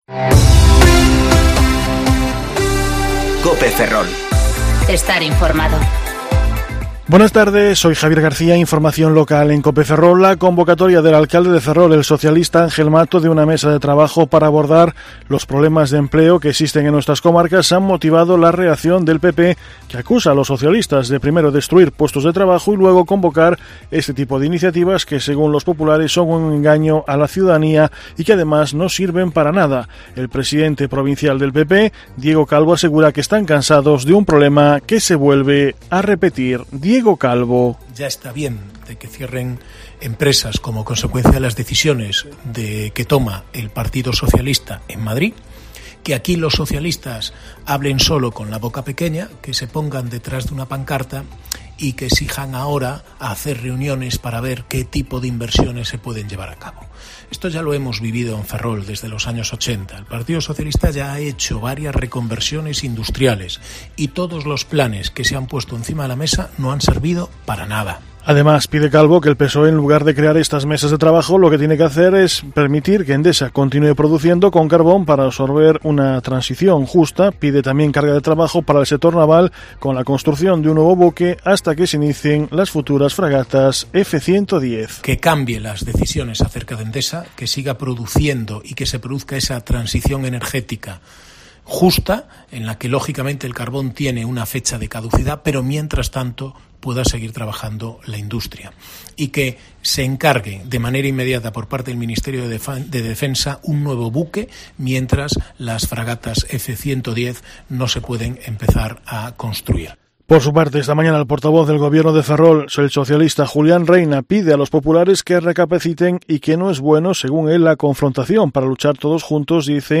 Informativo Mediodía COPE Ferrol 13/01/2020 (De 14,20 a 14,30 horas)